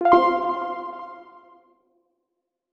Interface Soft Button.wav